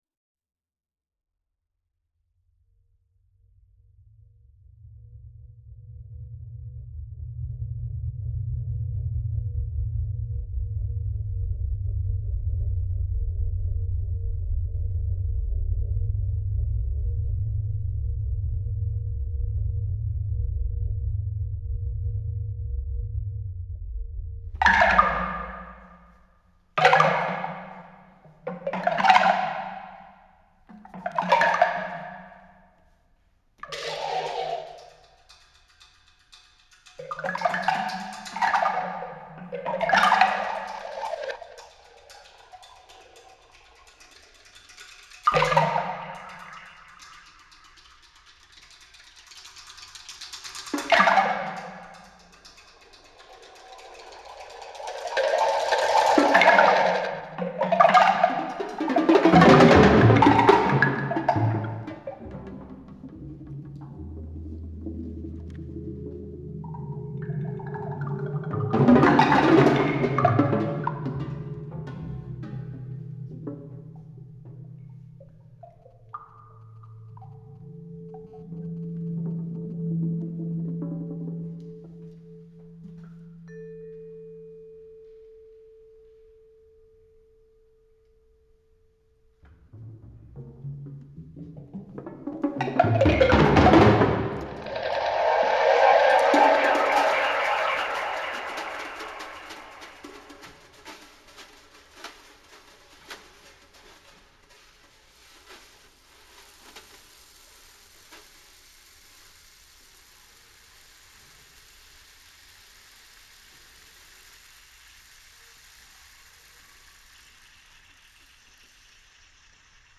for 6 percussionists & electronics
April 3, 2013 // Pollack Hall, Montréal, QC